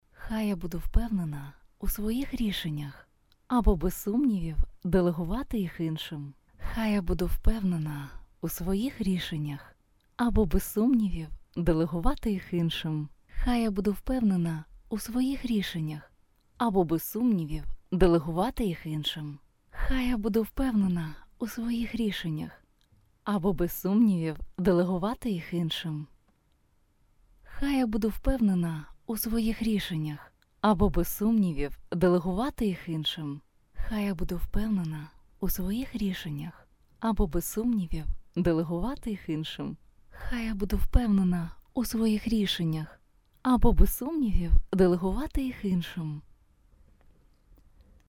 Рекордер Zoom H6. Появились шумы при записи.
Подскажите, пожалуйста,кто сталкивался с проблемой, два года писала идеальный звук, рекламные ролики,озвучку, сейчас только надеваю наушники-слышу шум( Отработал?Возможно решить проблему?